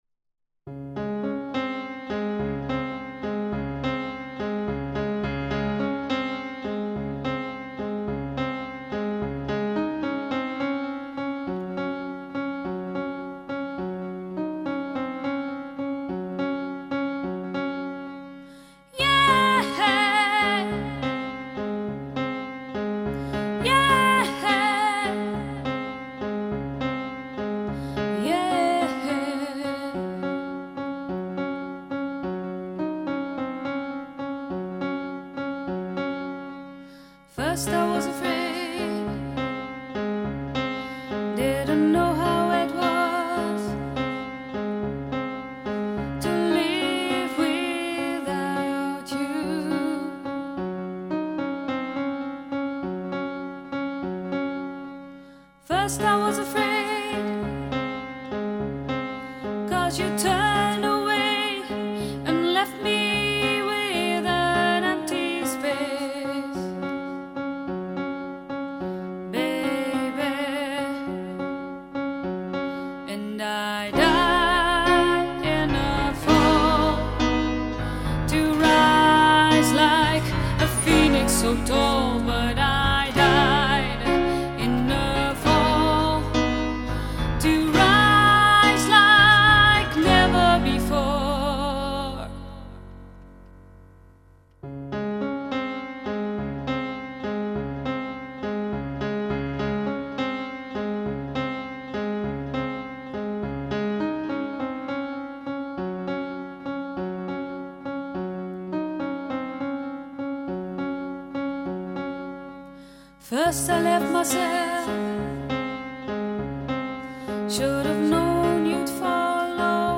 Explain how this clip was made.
Recorded at Rayment's Hill Studio